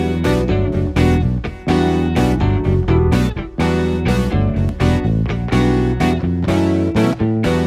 32 Backing PT2.wav